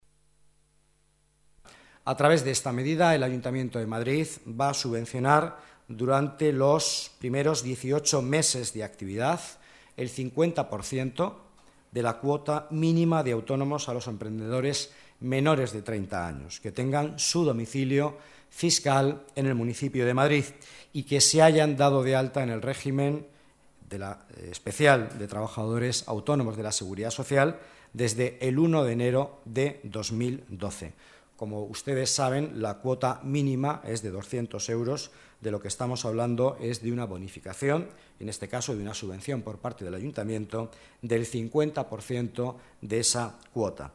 Nueva ventana:Declaraciones vicealcalde, Miguel Ángel Villanueva: ayuda a jóvenes emprendedores